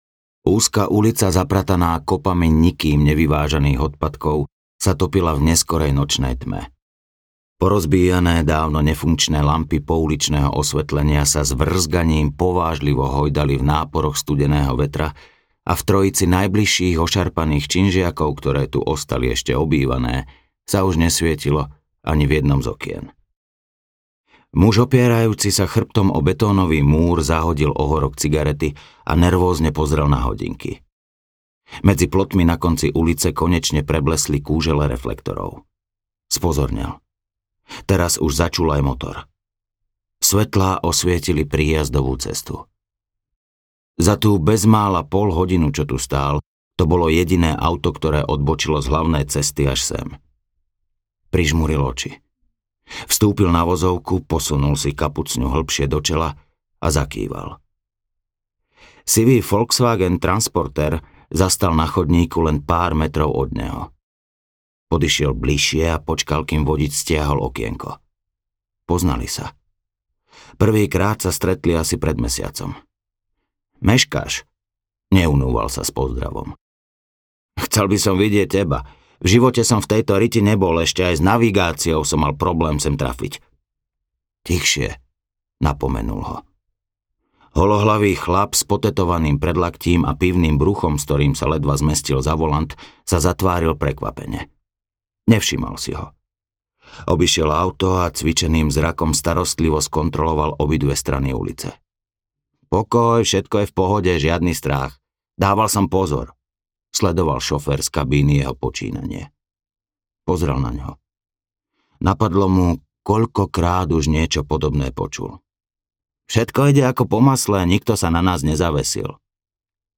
Pikový kráľ audiokniha
Ukázka z knihy
pikovy-kral-audiokniha